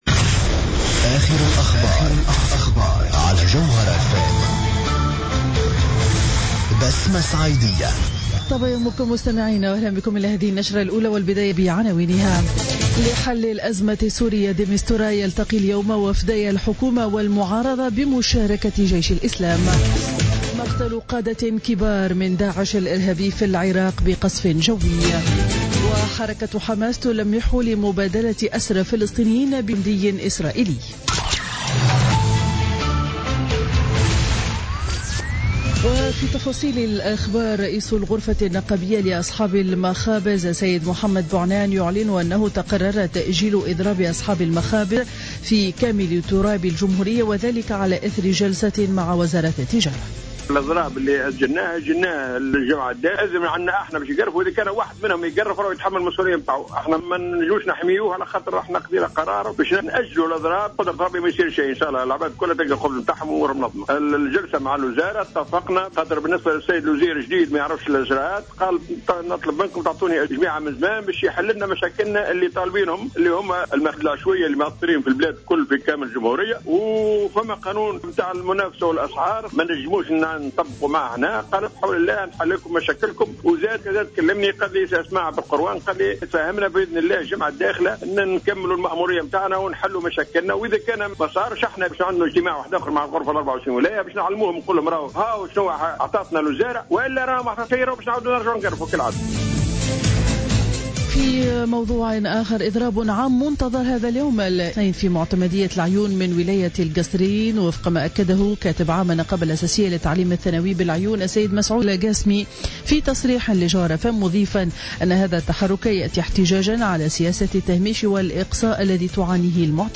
نشرة أخبار السابعة صباحا ليوم الاثنين 01 فيفري 2016